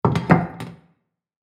Free Home and Household Sound Effects.
Plate-placing-down-on-table-2.mp3